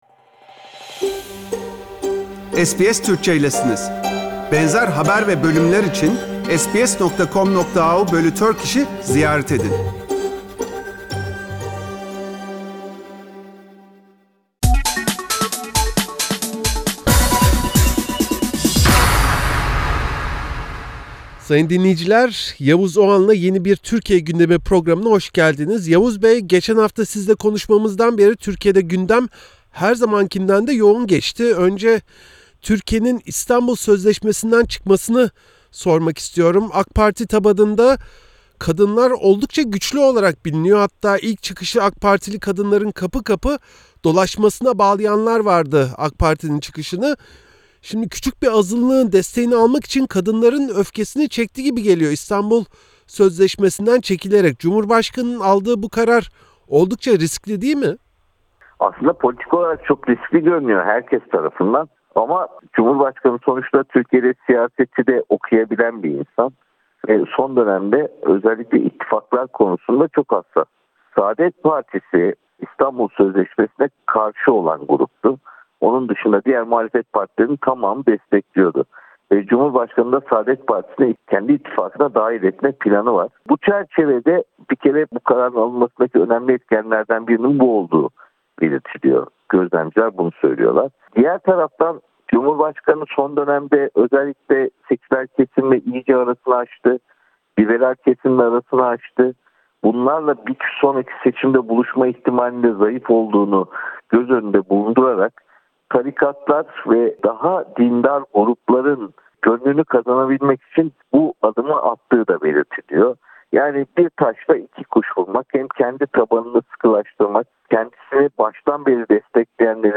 Gazeteci Yavuz Oğhan SBS Türkçe için gündemi değerlendirdi.